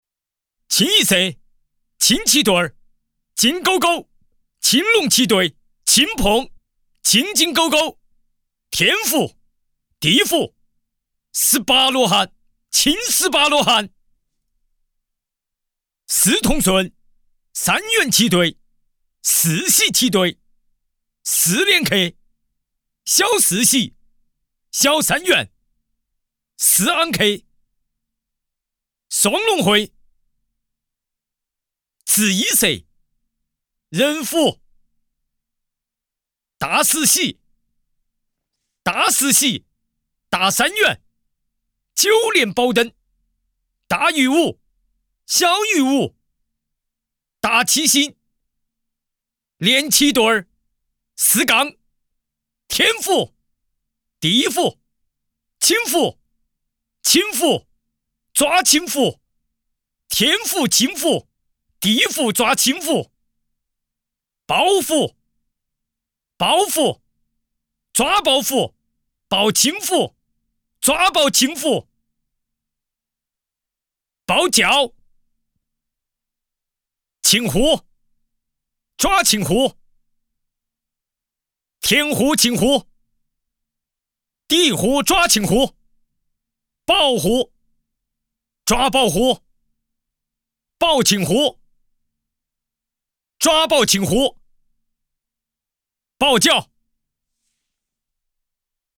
男12